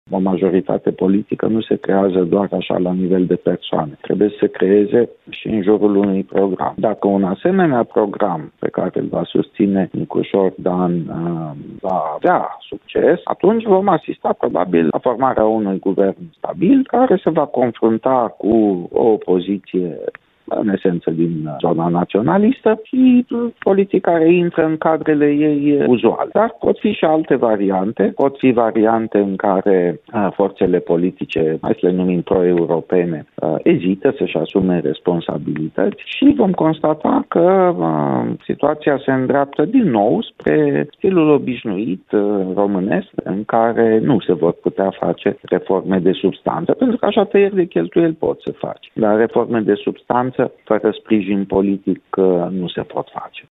Într-un comentariu oferit pentru postul nostru de radio